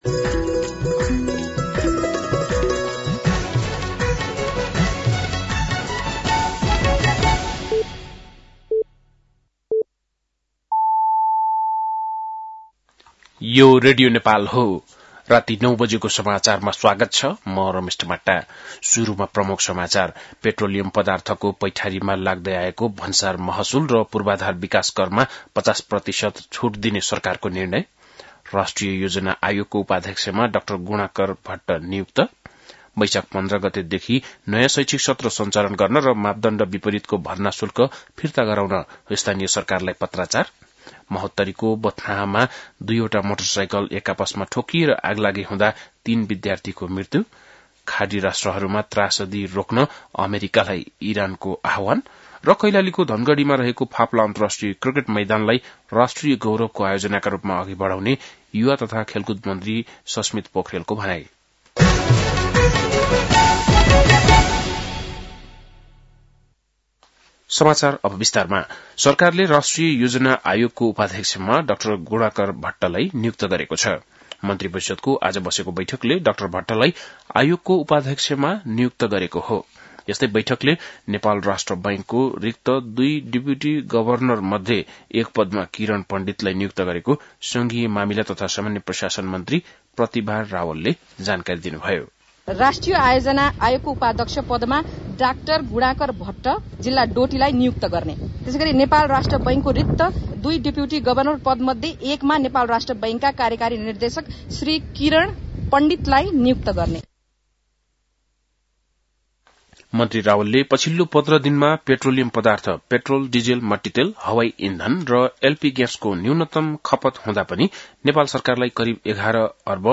बेलुकी ९ बजेको नेपाली समाचार : २४ चैत , २०८२
9-PM-Nepali-NEWS-12-24.mp3